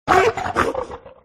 pokerogue / public / audio / cry / 910.ogg